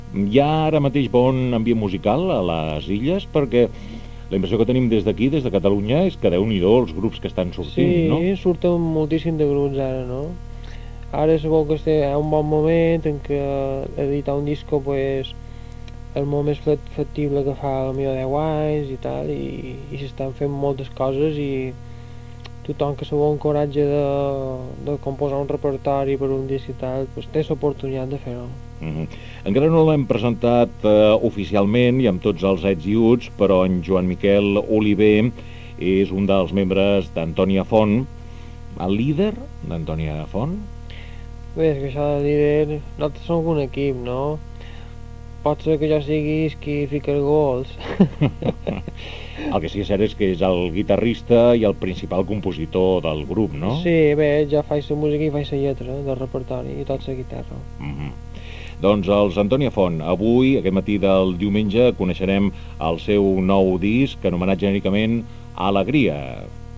Inici de l'entrevista a Joan Miquel Oliver del grup Antònia Font, pel seu disc "Alegria"